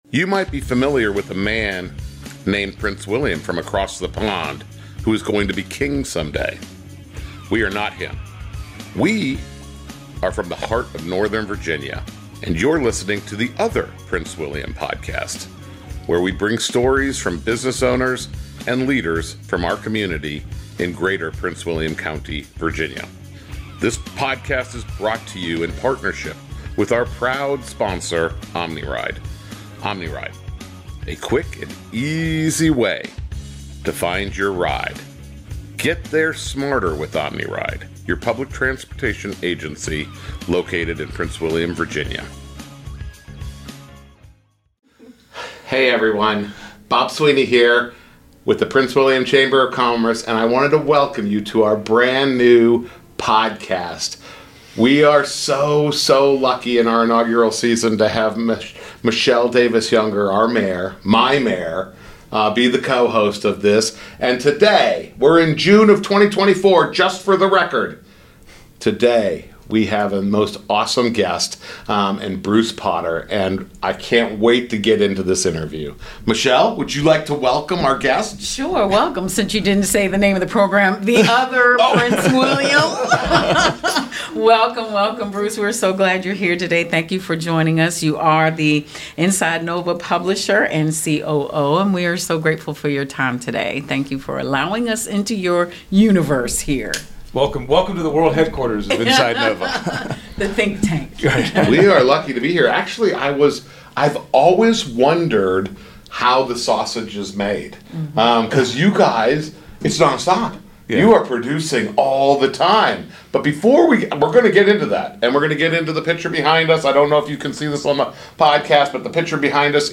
** Episode was filmed in June 2024 at InsideNoVa's headquarters in Woodbridge, Virginia.